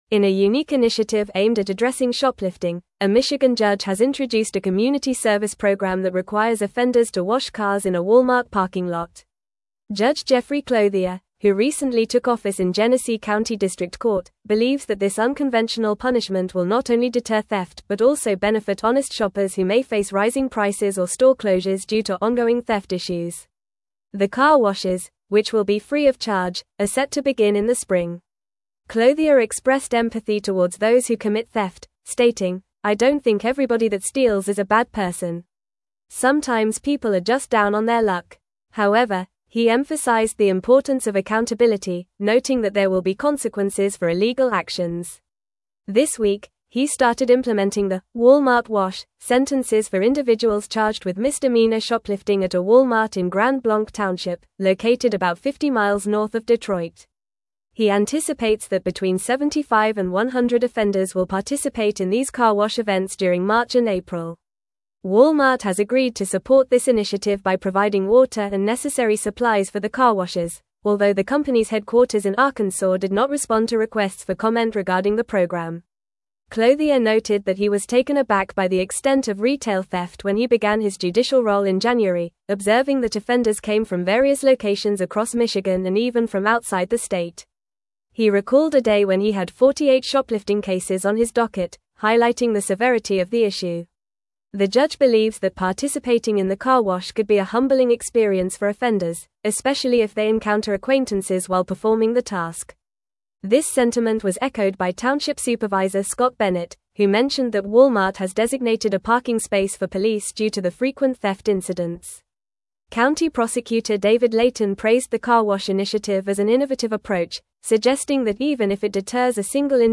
Fast
English-Newsroom-Advanced-FAST-Reading-Michigan-Judge-Introduces-Unique-Community-Service-for-Shoplifters.mp3